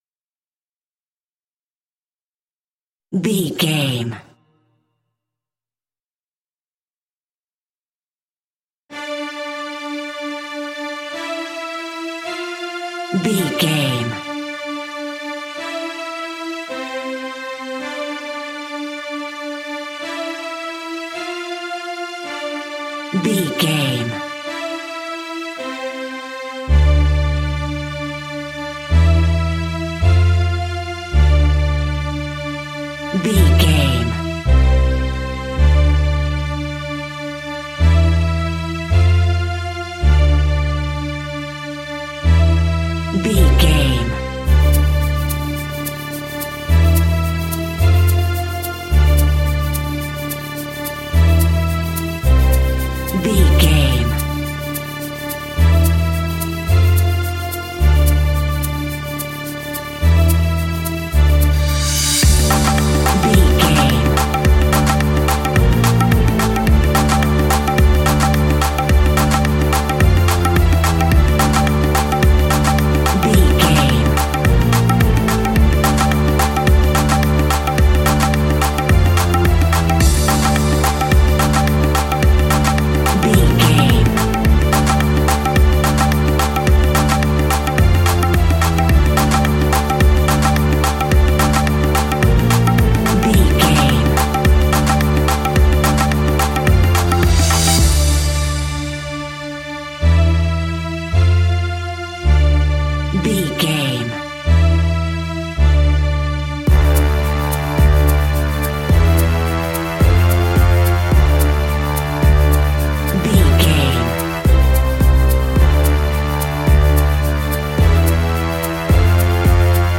Aeolian/Minor
percussion
congas
bongos
kora
djembe
kalimba
marimba